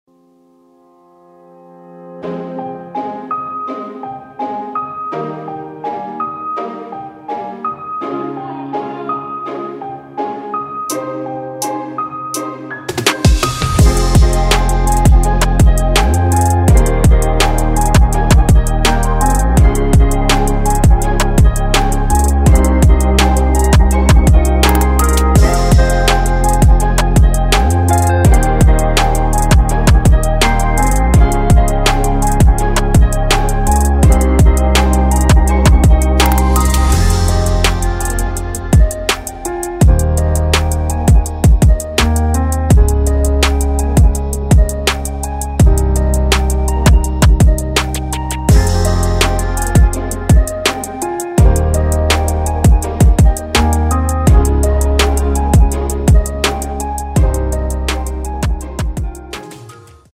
歌曲调式：升G大调